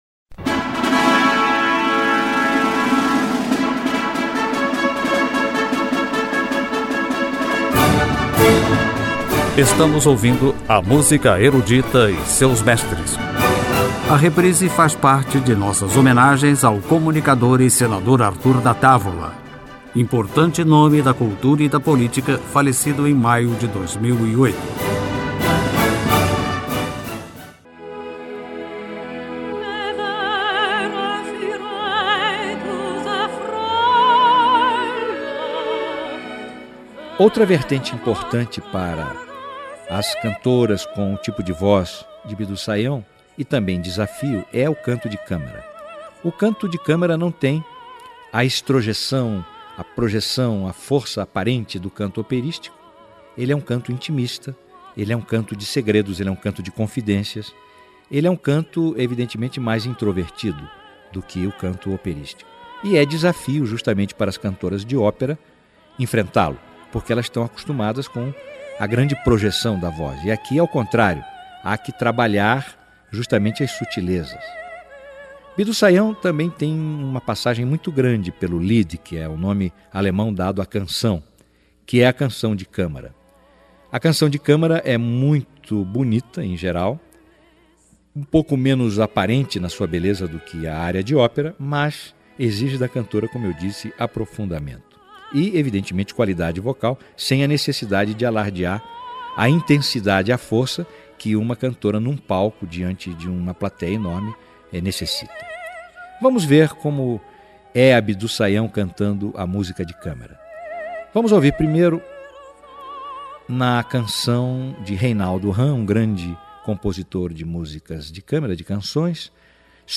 Canto de Câmara
com a Orquestra Sinfônica Colúmbia
Música Erudita
Cantores líricos